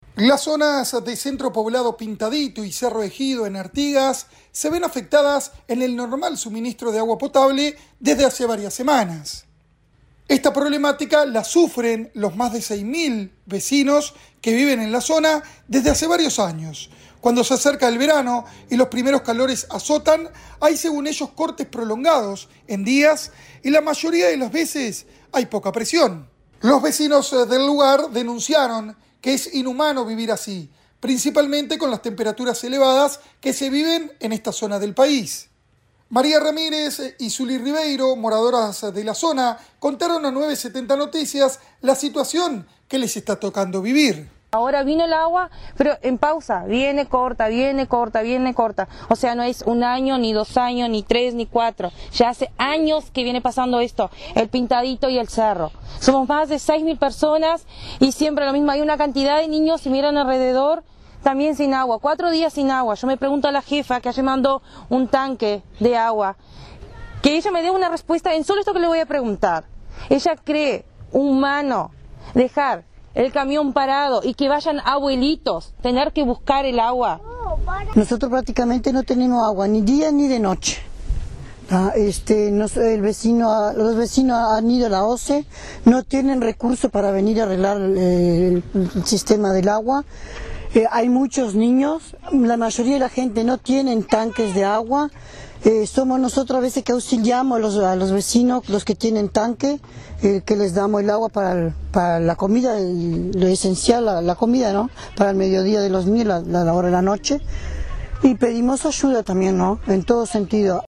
El informe del corresponal para 970 Noticias